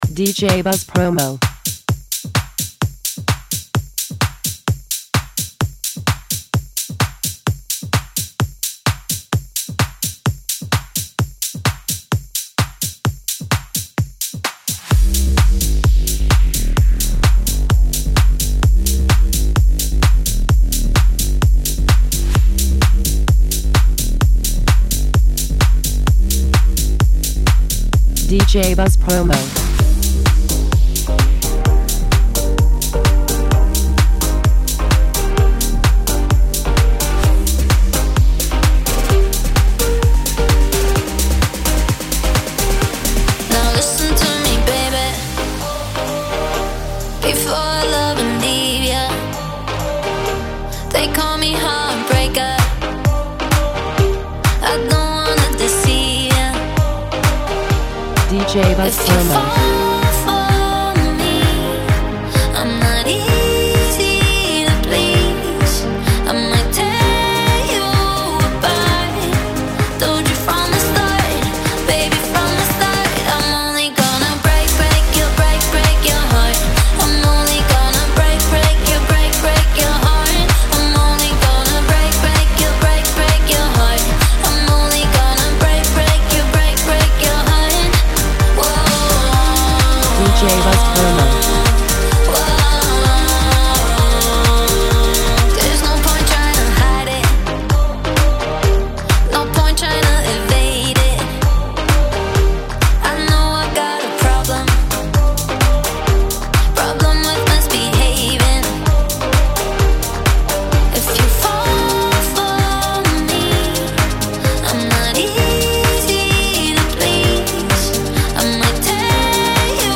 Extended
infused with pulsating beats and infectious grooves